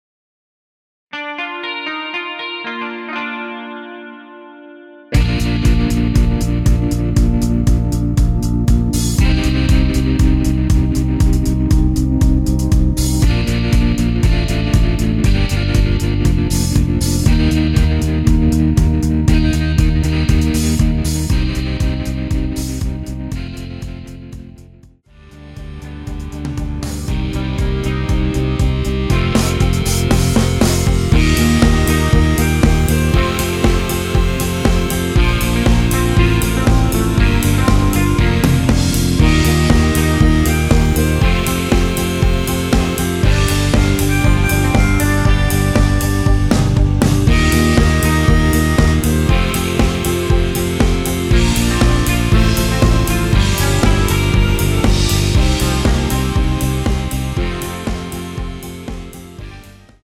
원키에서(-1)내린 MR입니다.
Bb
앞부분30초, 뒷부분30초씩 편집해서 올려 드리고 있습니다.